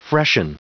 Prononciation du mot freshen en anglais (fichier audio)
Prononciation du mot : freshen